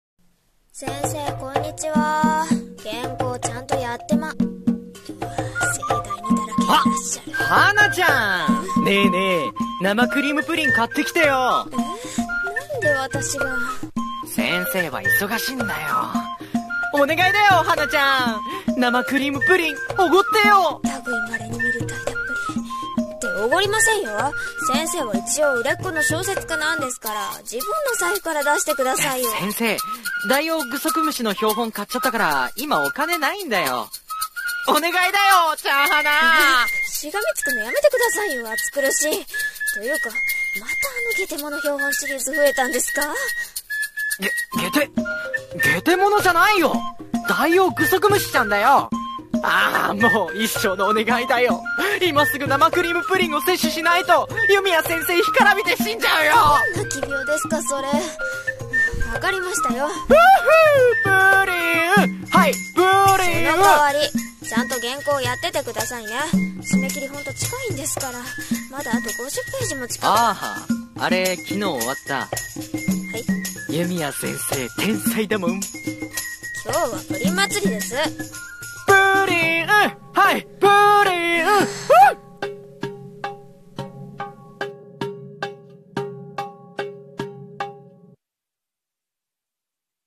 【声劇】天才作家と甘味なお昼時